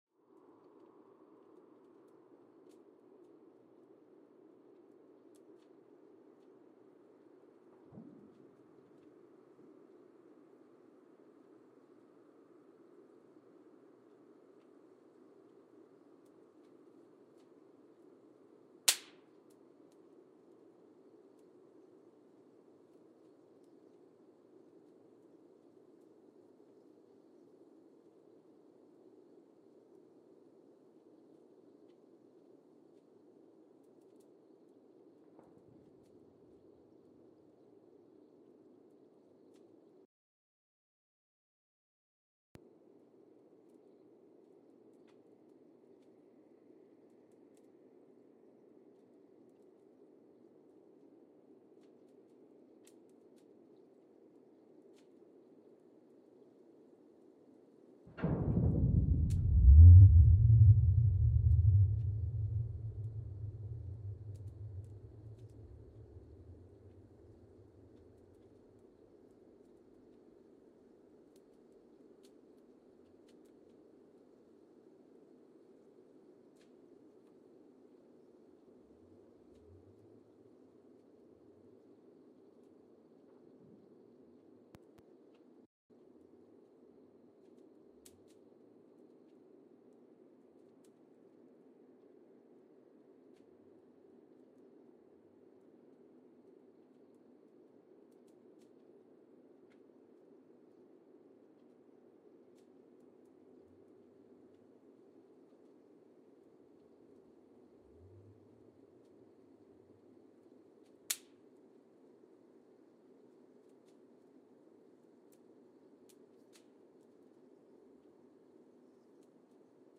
Mbarara, Uganda (seismic) archived on September 13, 2017
Station : MBAR (network: IRIS/IDA) at Mbarara, Uganda
Sensor : Geotech KS54000 triaxial broadband borehole seismometer
Speedup : ×1,800 (transposed up about 11 octaves)
Loop duration (audio) : 05:36 (stereo)
SoX post-processing : highpass -2 90 highpass -2 90